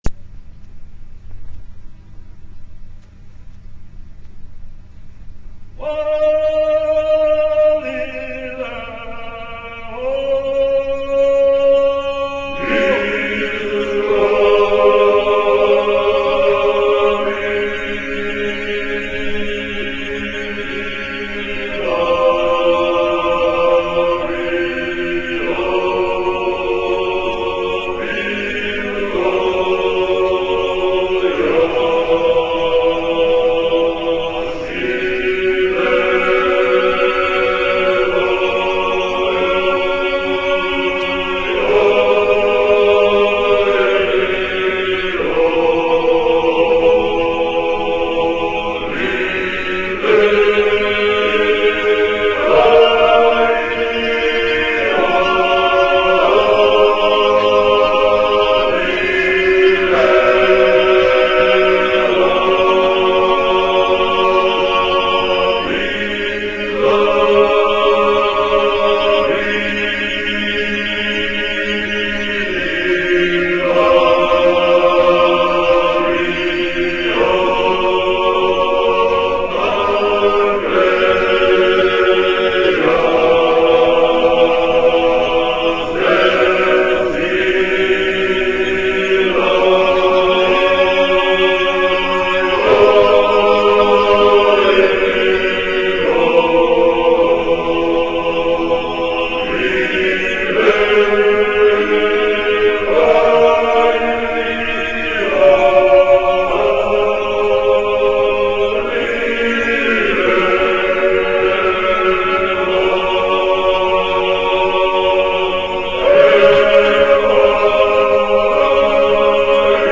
Music of Georgia - Folk Songs And Dances